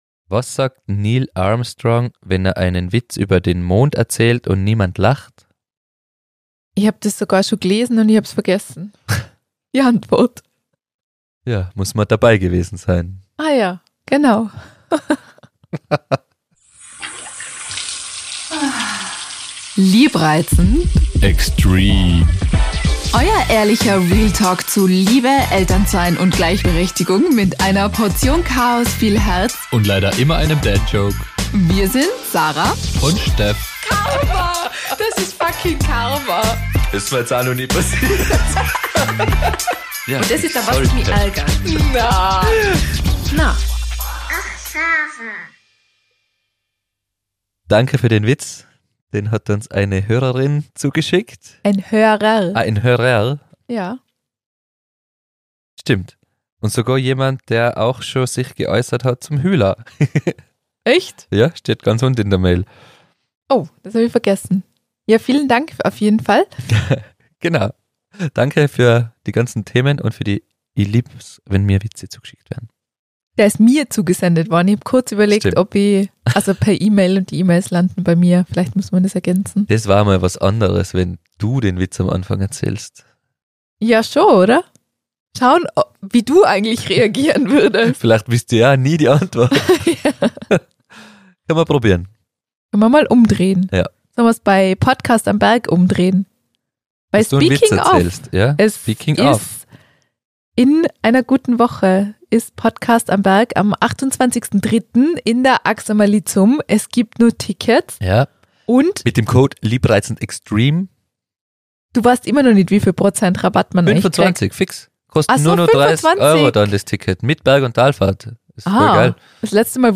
Aufgrund einiger Bezugnahmen zu den letzten Folgen und dem Fall vom Tod am Großglockner steigen die zwei nochmals in dieses Thema ein und versuchen nochmals mit einer feministischen Brille das Verhalten von Männern am Berg zu hinterfragen. Achtung es wird hitzig diskutiert